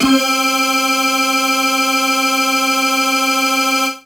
55bg-syn15-c4.wav